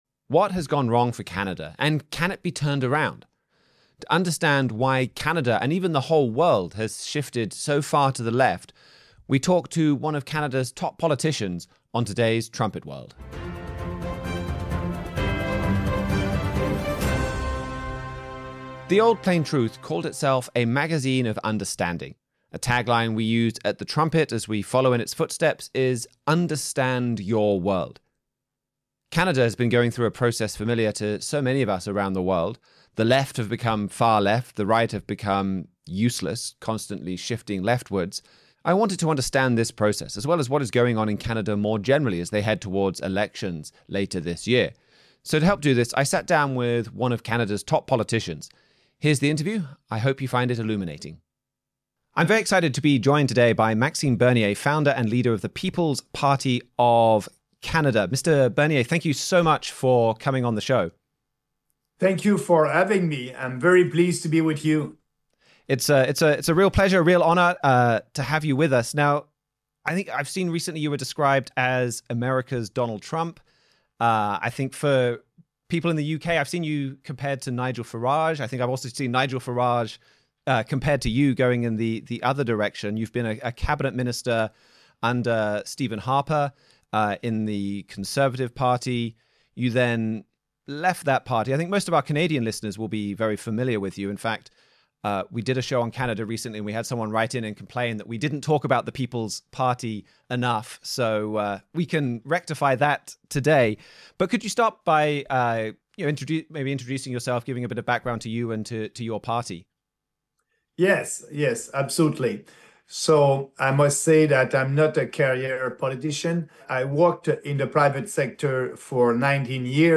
We have a special guest for today’s show: Maxime Bernier, founder of the People’s Party of Canada.
Join the discussion as Trumpet staff members compare recent news with Bible prophecy.
trumpet-world-50-can-canada-be-fixed-interview-with-maxime-bernier.mp3